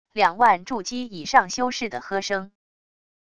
两万筑基以上修士的喝声wav音频